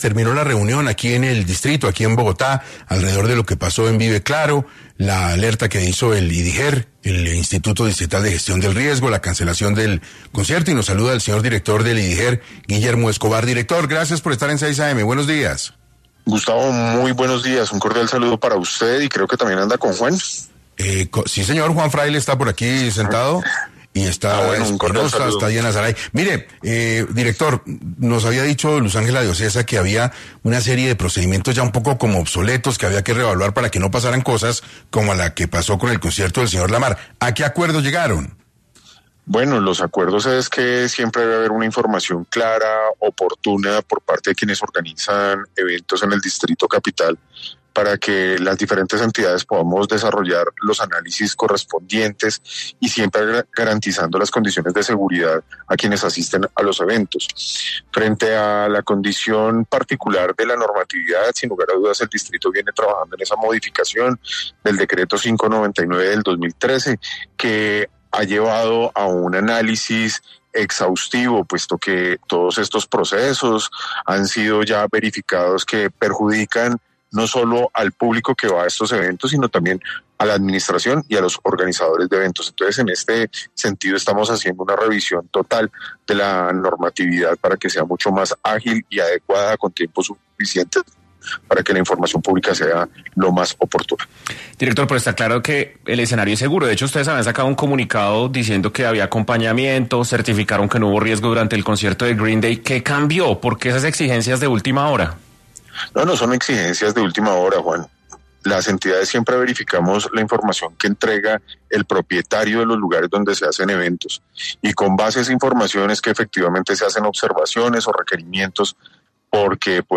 Guillermo Escobar, director del Instituto Distrital de Gestión del Riesgo (Idiger), pasó por 6AM para aclarar varios puntos asociados a la cancelación del concierto de Kendrick Lamar.